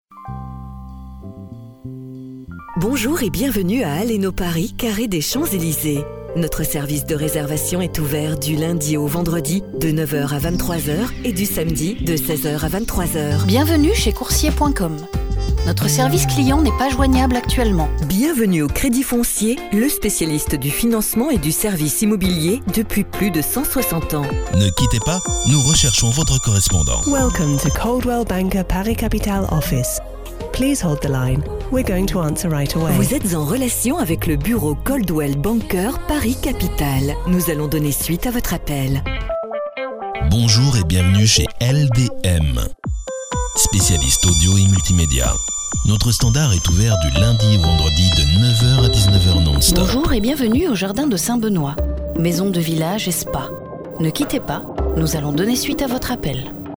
Voix off pro 100%
Musiques libres de droits 100%
Exemples d’un message répondeur professionnel, attente et pré-décroché.